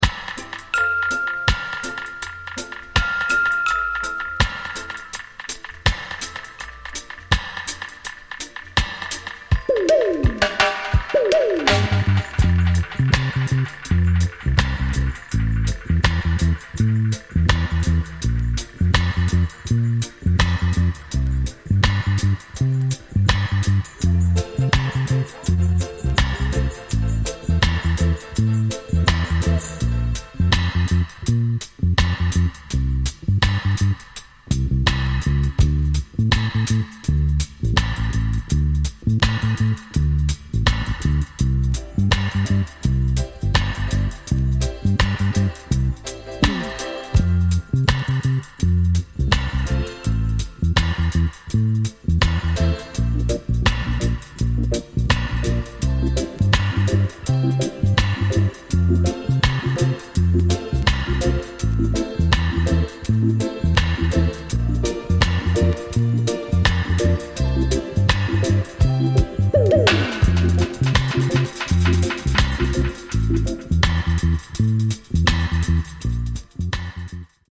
Lovers from the late ’80s-early ’90s.